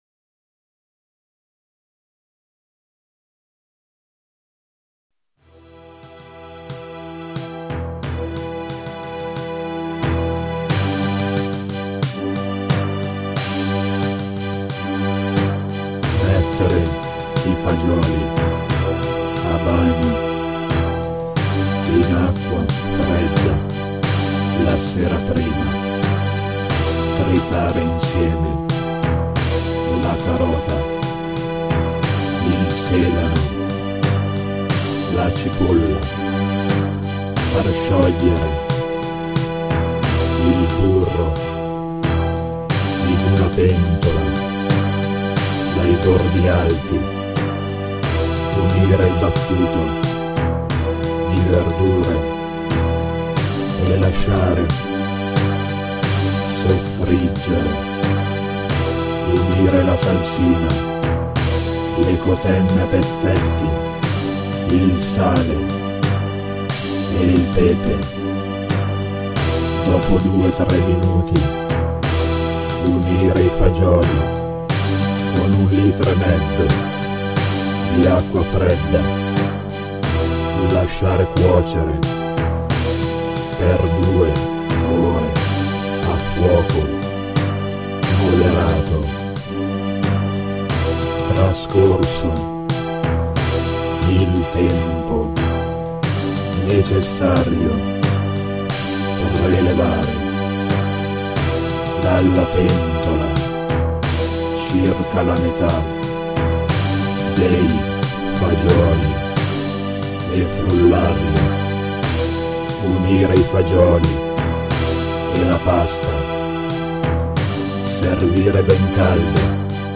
(Demo Studio/Midi - 2004)